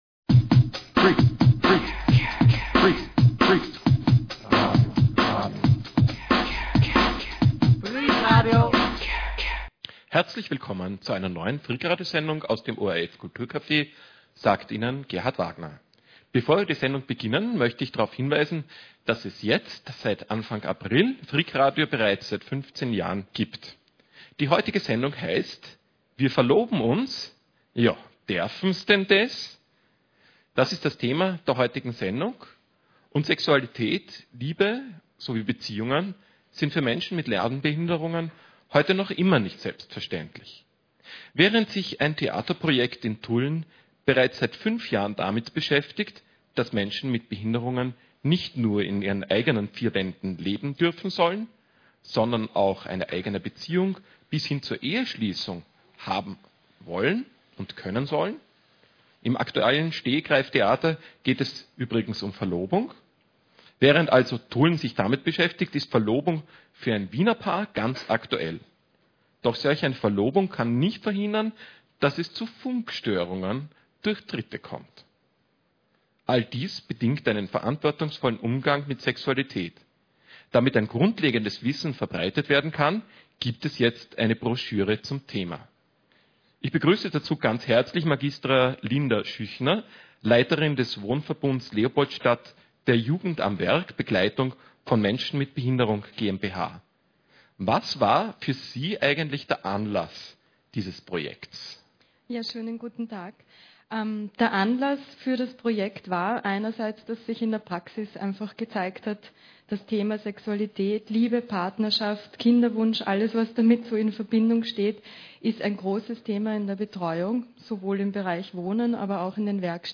Gäste im KulturCafe (©Miteinander leben, Tulln)